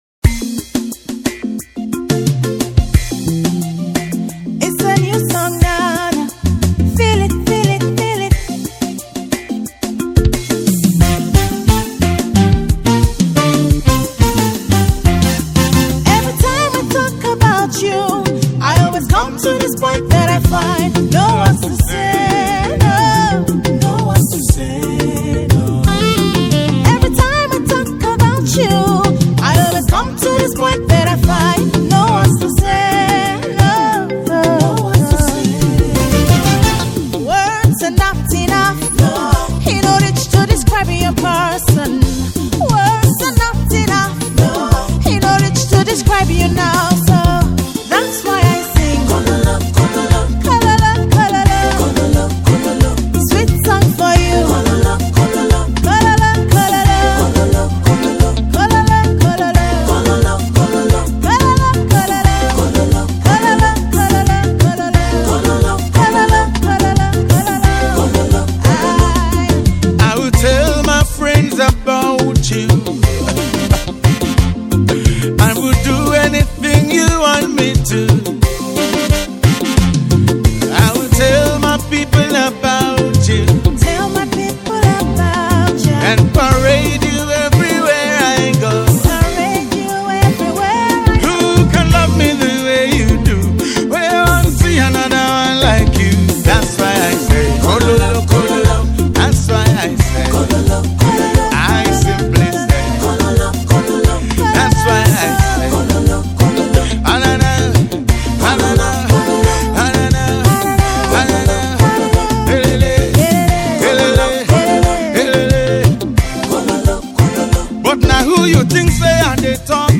March 12, 2025 Publisher 01 Gospel 0
poet spoken words and reggae gospel artist.